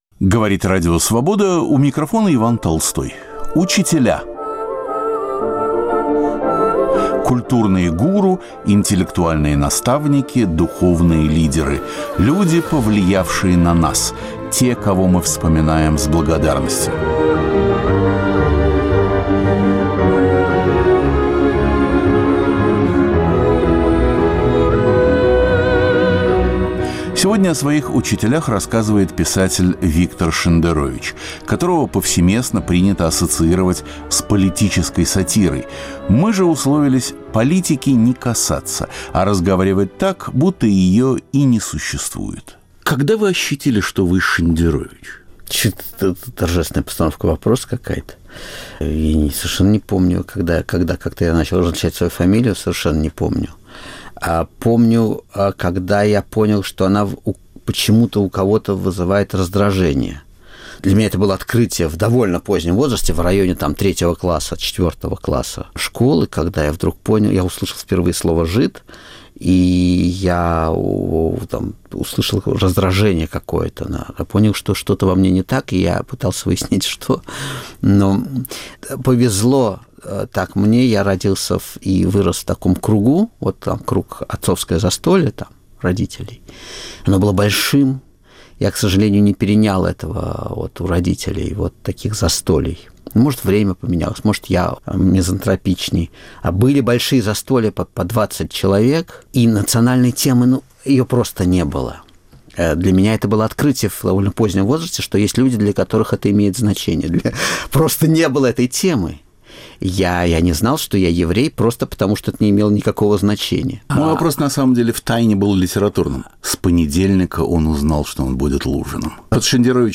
В серии "Учителя" разговор с писателем-сатириком, побывавшим в Праге. Никакой политики. Чистая литература.